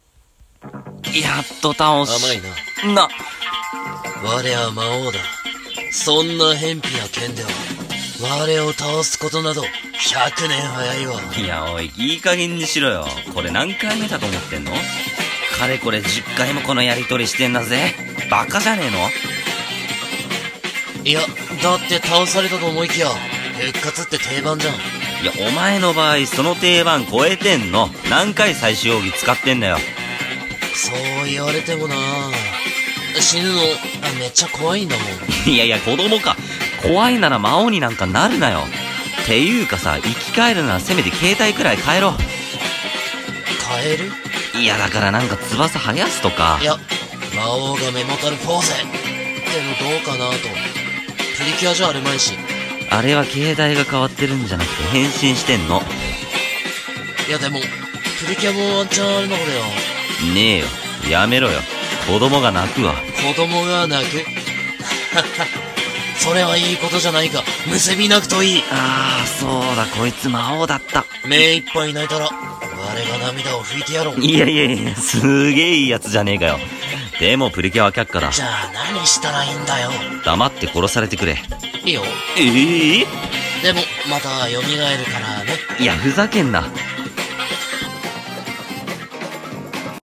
魔王は何度でも蘇るのさっ★【ギャグ掛け合い】